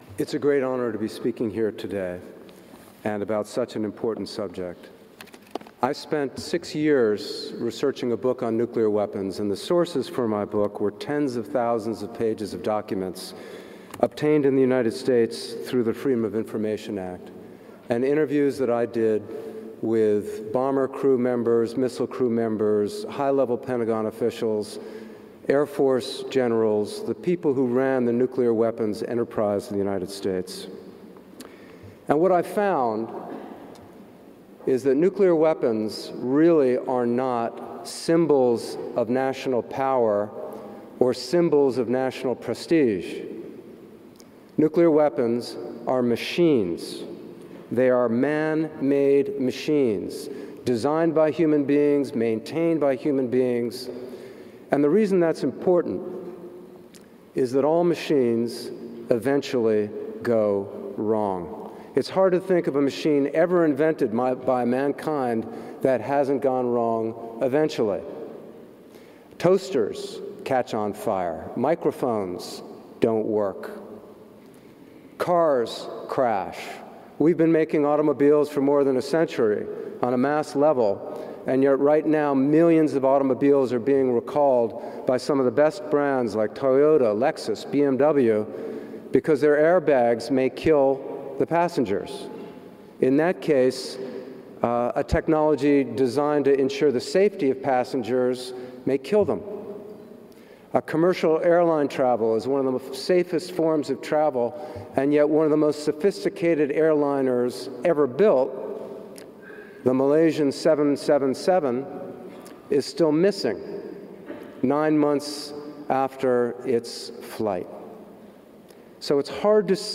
This presentation of Eric Schlosser was recorded on 8 December 2014 at the Vienna Conference on the Humanitarian Impact of Nuclear Weapons , at the Hofburg Vienna , in Austria.